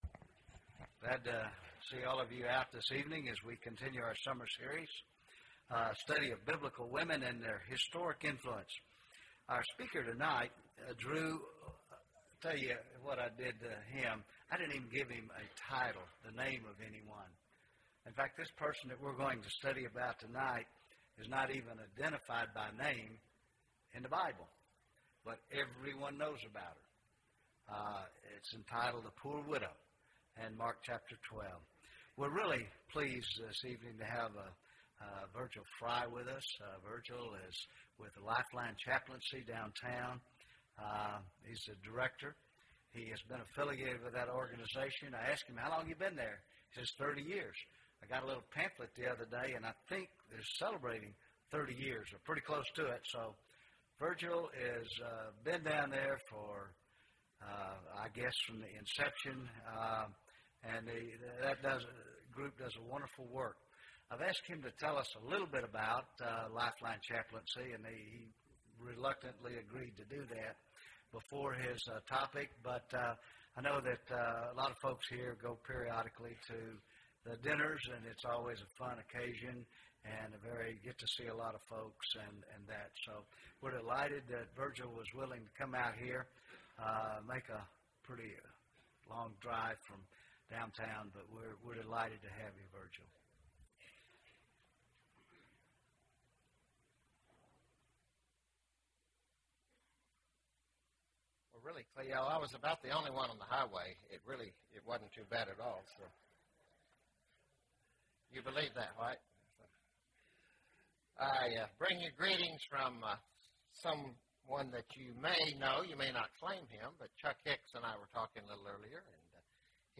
Poor Widow (8 of 10) – Bible Lesson Recording